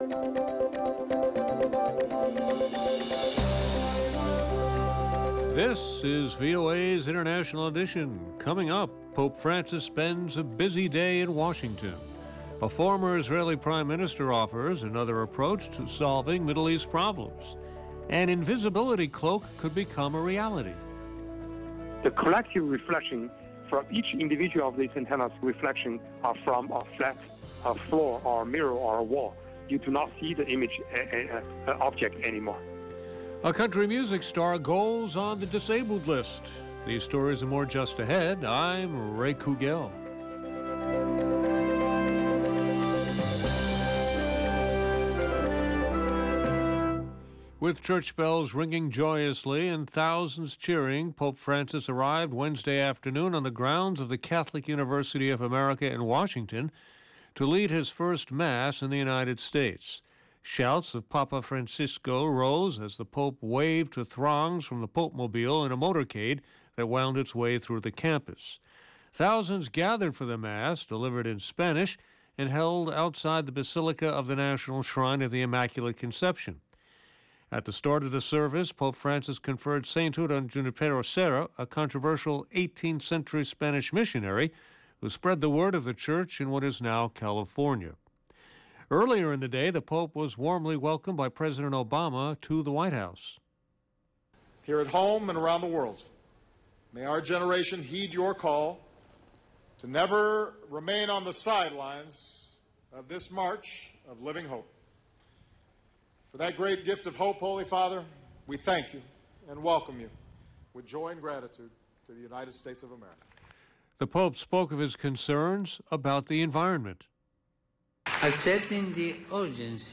LIVE at 0330 UTC, Replayed at 0430, 0530 and 0630 UTC - International Edition delivers insight into world news through eye-witnesses, correspondent reports and analysis from experts and news makers. We also keep you in touch with social media, science and entertainment trends.